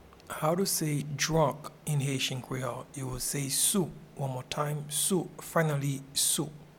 Pronunciation and Transcript:
Drunk-in-Haitian-Creole-Sou.mp3